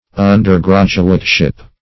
Search Result for " undergraduateship" : The Collaborative International Dictionary of English v.0.48: Undergraduateship \Un`der*grad"u*ate*ship\, n. The position or condition of an undergraduate.
undergraduateship.mp3